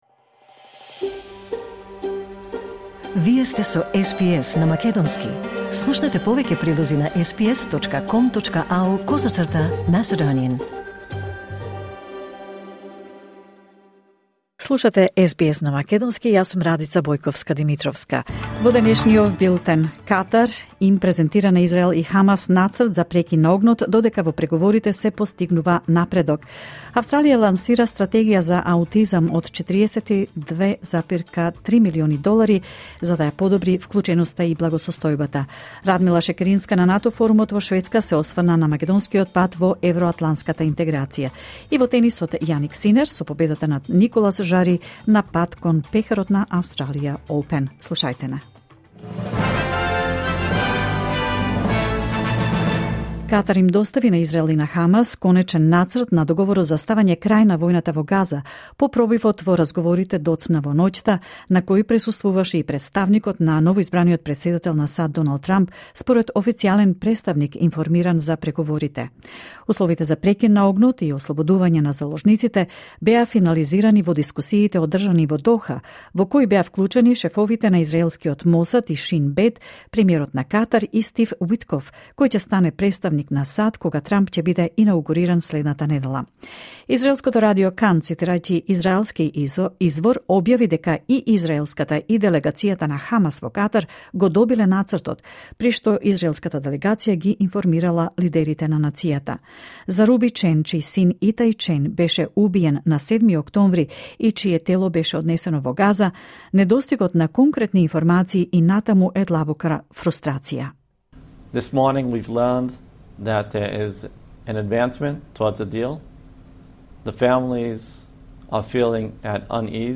SBS News in Macedonian 14 January 2025